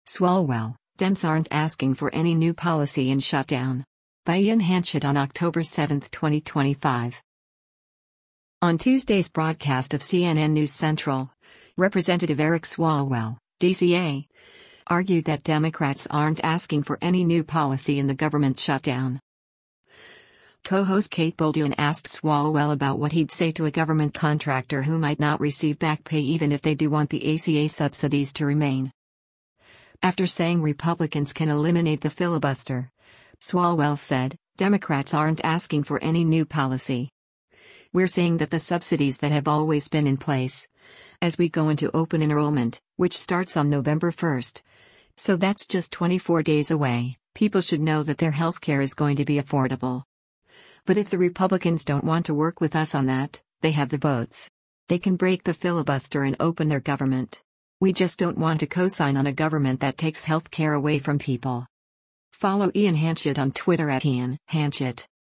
On Tuesday’s broadcast of “CNN News Central,” Rep. Eric Swalwell (D-CA) argued that “Democrats aren’t asking for any new policy” in the government shutdown.
Co-host Kate Bolduan asked Swalwell about what he’d say to a government contractor who might not receive back pay even if they do want the ACA subsidies to remain.